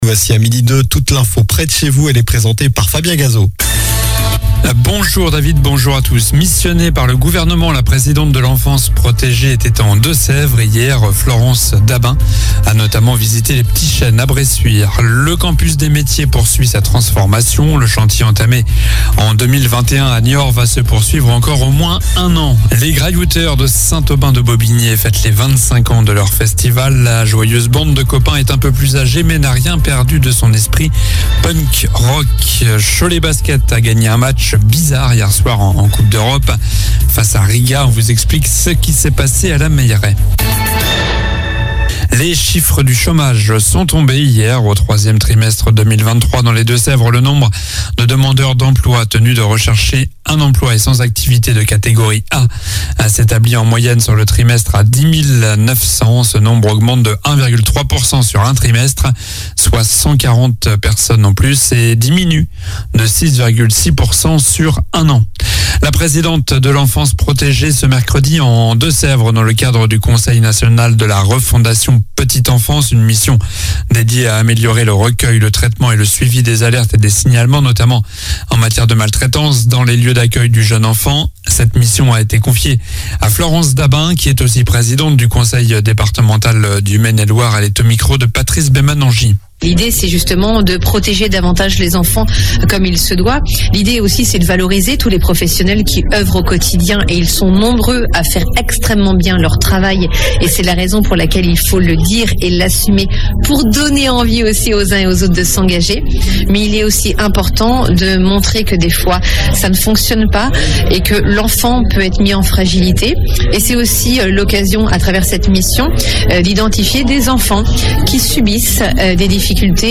Journal du jeudi 26 octobre (midi)